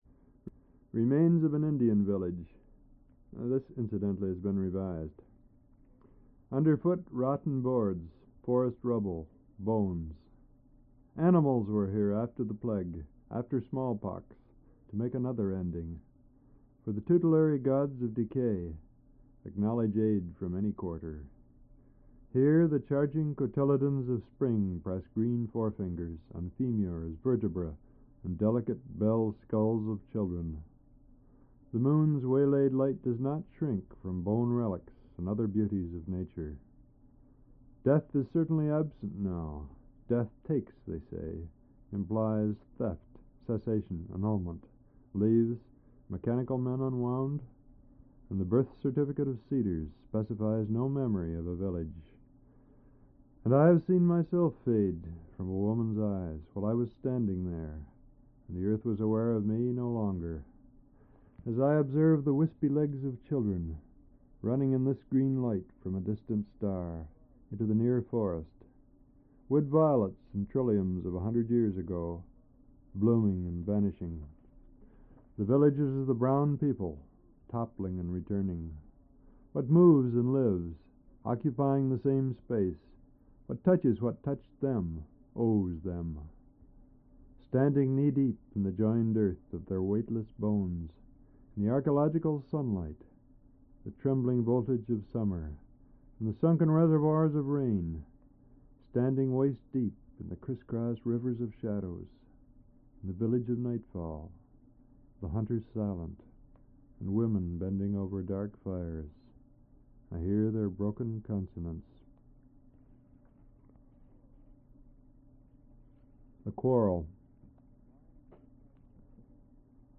1/4"' Reel-to-Reel Tape